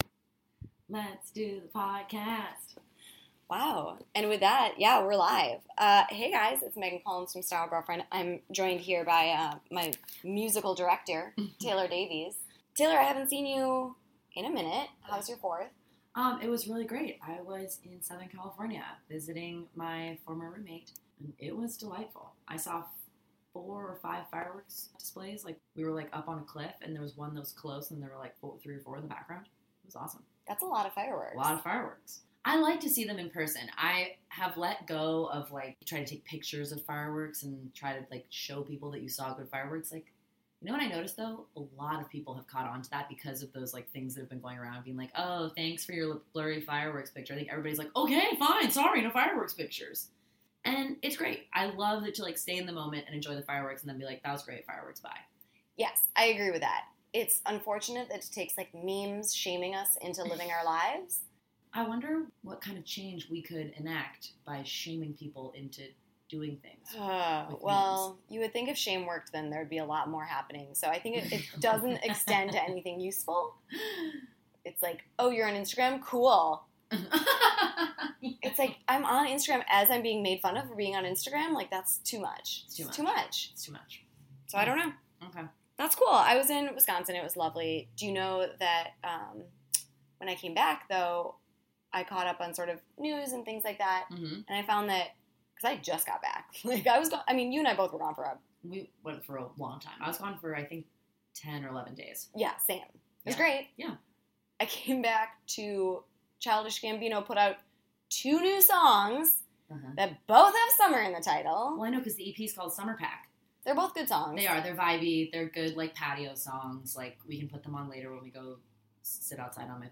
We had a great time getting back in the studio booth
on a very noisy Second Avenue